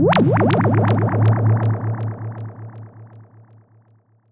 Index of /musicradar/sci-fi-samples/Theremin
Theremin_FX_17.wav